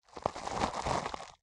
Minecraft Version Minecraft Version latest Latest Release | Latest Snapshot latest / assets / minecraft / sounds / block / chorus_flower / death2.ogg Compare With Compare With Latest Release | Latest Snapshot
death2.ogg